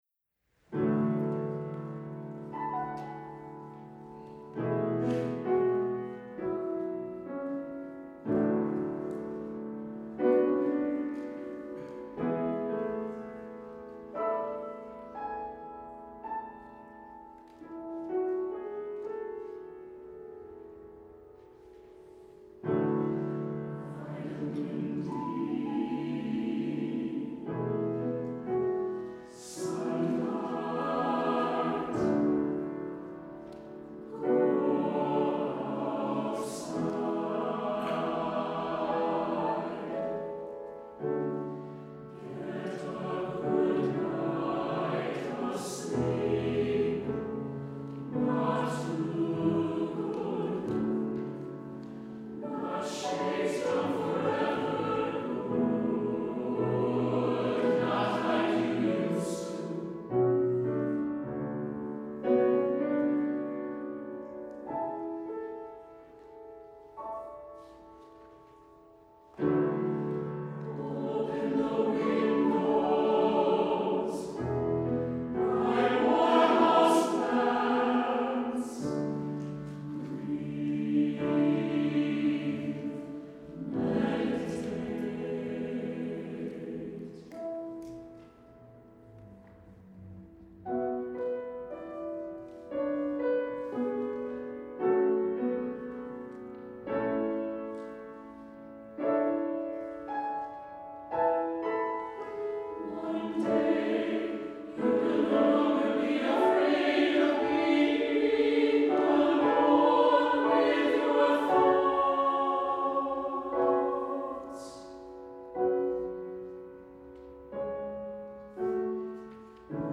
Uplifting, motivating, life-changing.
SATB, piano
Empowering, supportive, emotional, relevant.